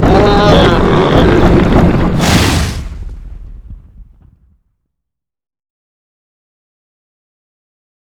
bearcharge.wav